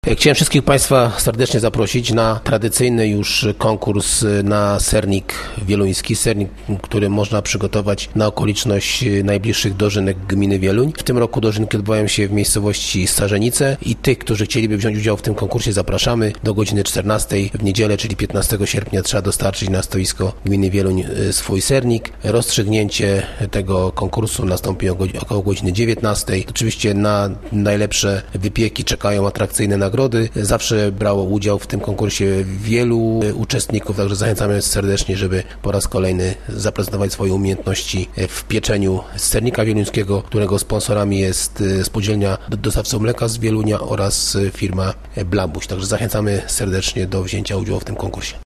Do zmagań cukierniczych zachęca burmistrz Wielunia, Paweł Okrasa: